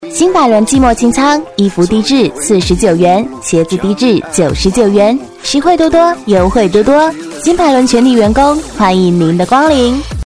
女-002号-列表页